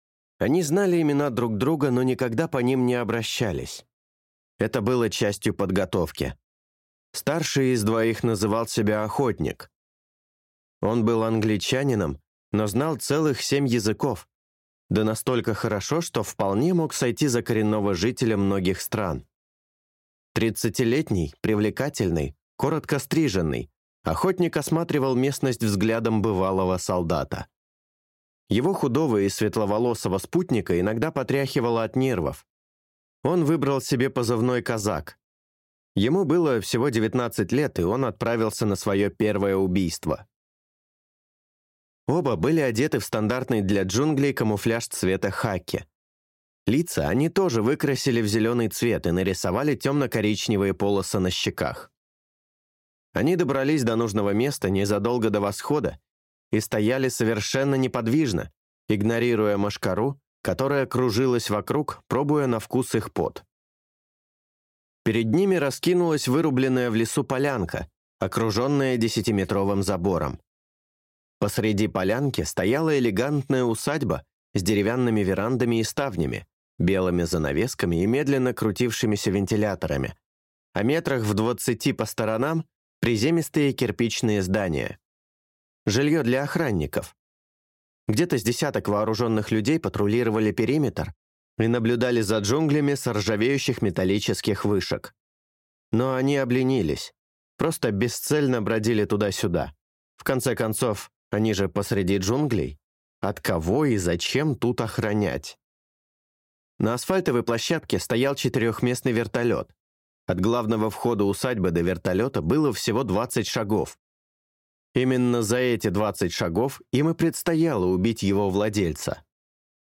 Аудиокнига Удар Орла | Библиотека аудиокниг